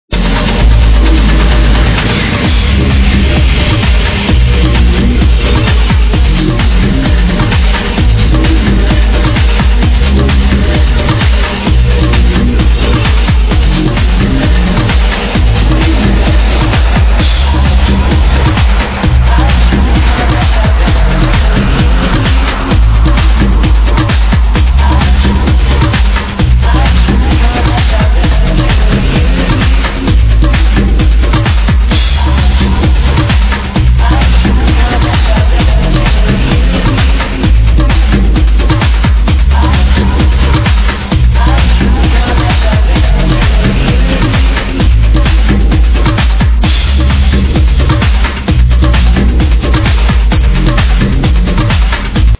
It's a remix for sure got any ideas on which one?
Sexy vibes all around...